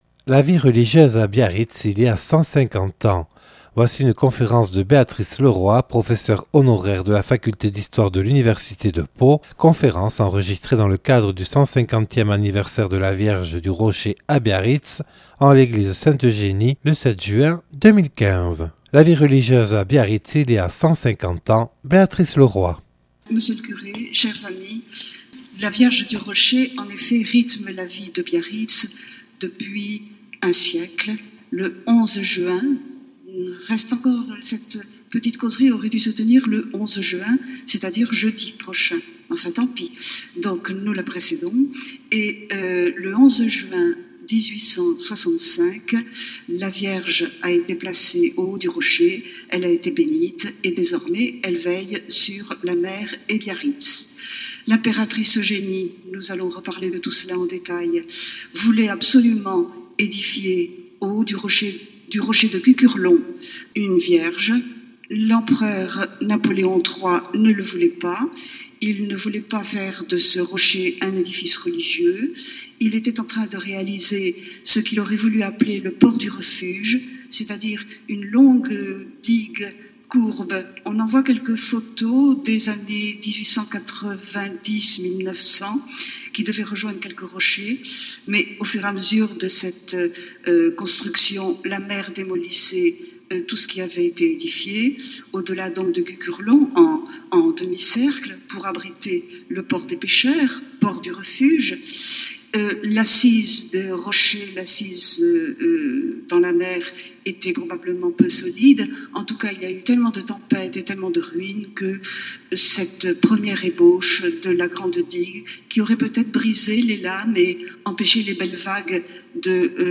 Une conférence
(Enregistré le 7/06/2015 à Biarritz).